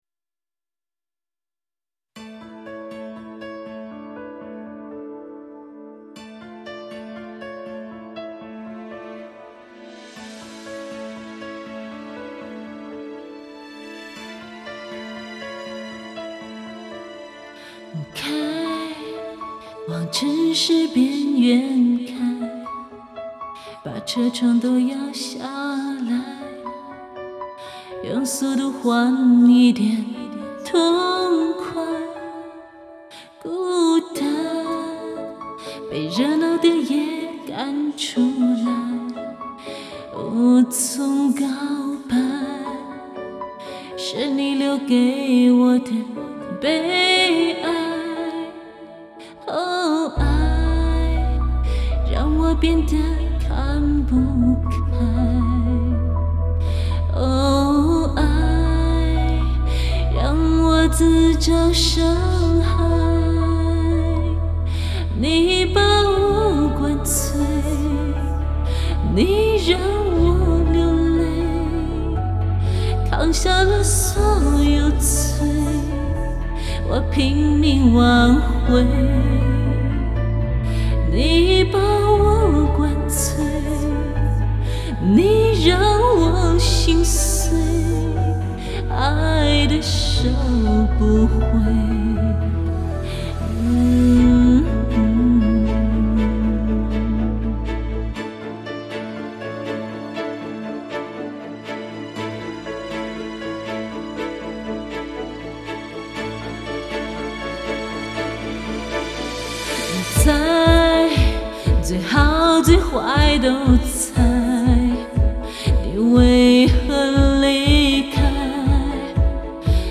好几年前录的，当时是一遍过录的，唱得倒是蛮过瘾，但觉得还少了那么点激情阿，早知道这样也喝点酒得了，哈哈。